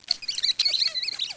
zp_rats.wav